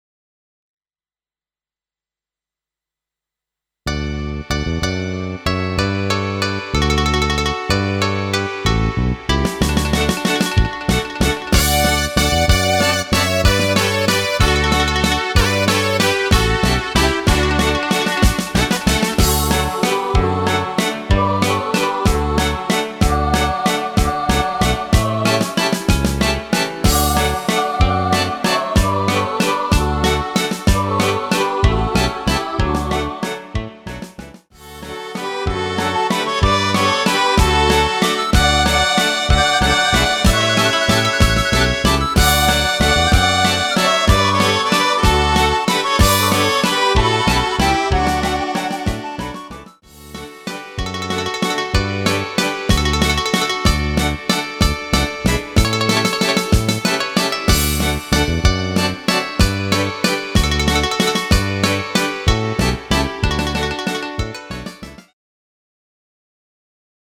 Rubrika: Pop, rock, beat
- valčík